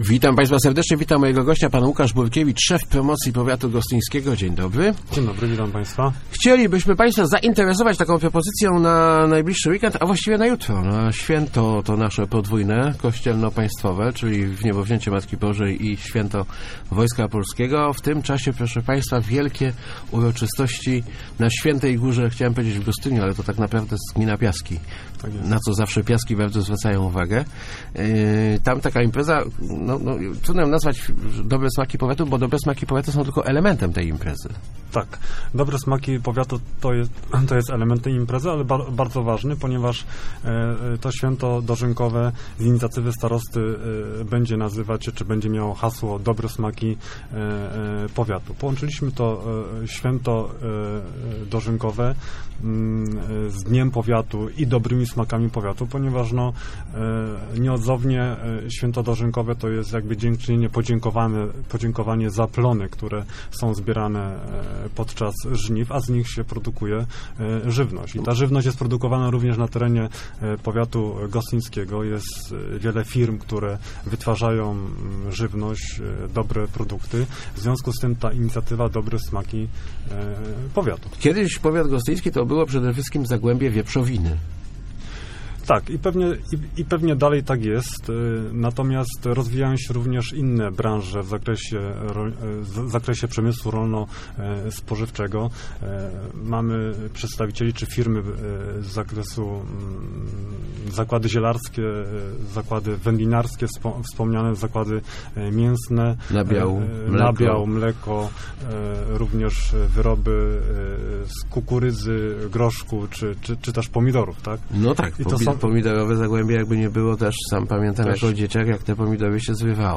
Start arrow Rozmowy Elki arrow Dobre smaki powiatu